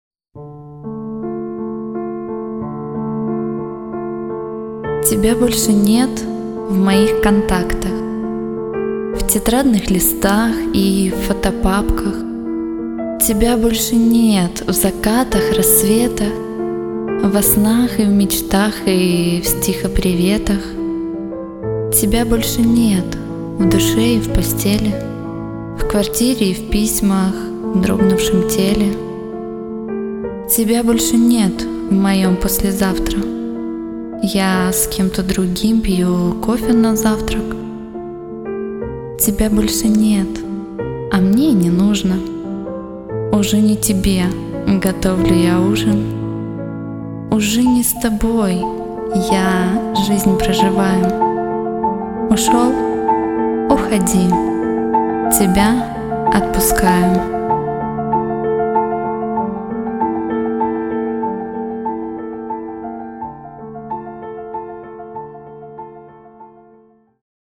yuliya_shilova_tebya_otpuskayu_audiostihi.mp3